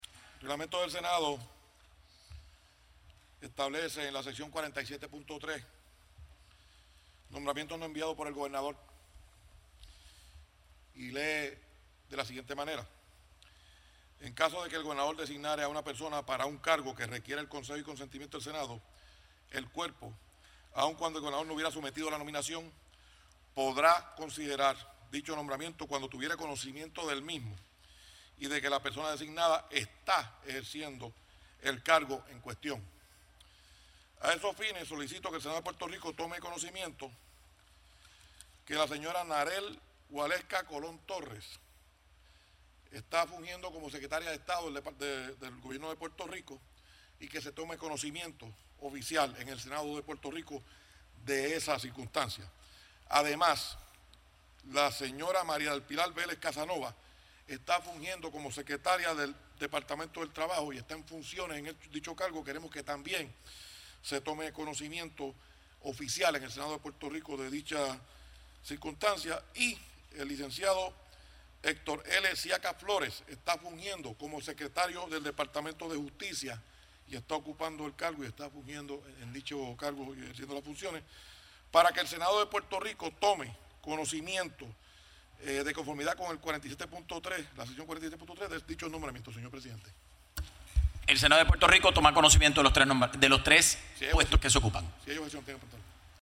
Sus expresiones las hizo durante la celebración de la sesión ordinaria en el Senado hoy, jueves, basándose en al Sección 47.3 del reglamento de dicho cuerpo legislativo.